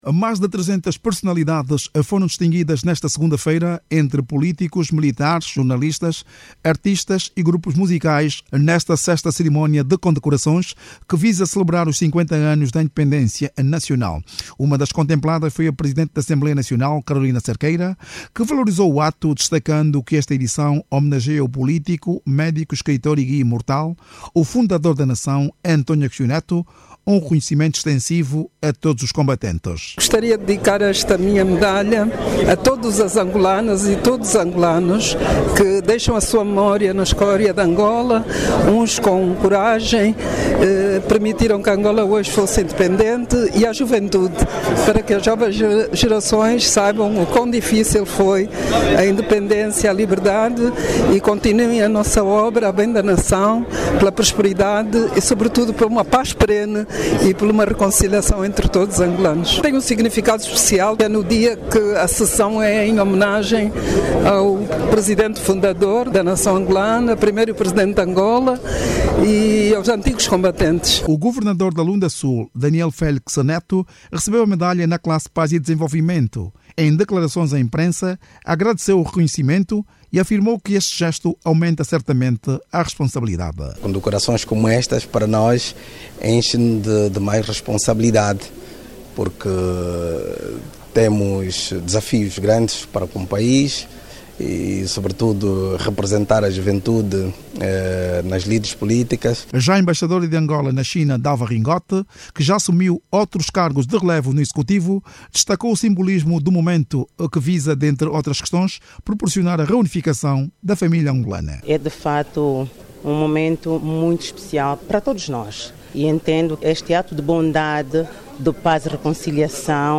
Jornalista